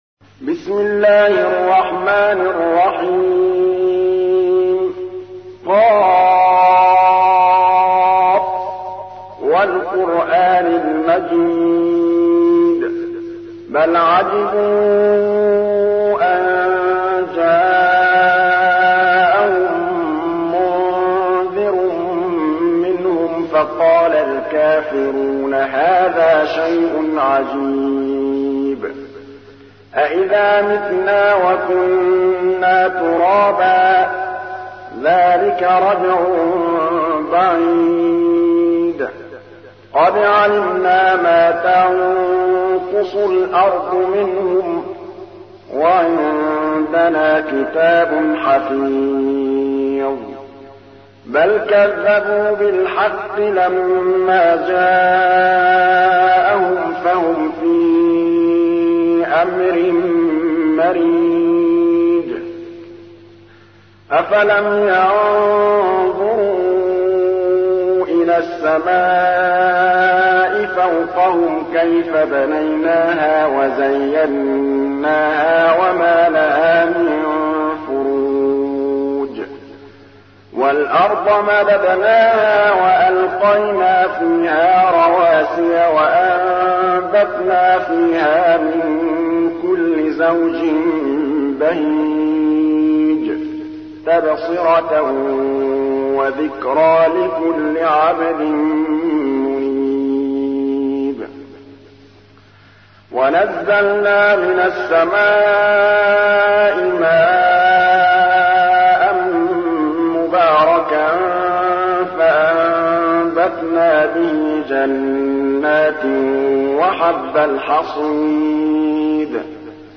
تحميل : 50. سورة ق / القارئ محمود الطبلاوي / القرآن الكريم / موقع يا حسين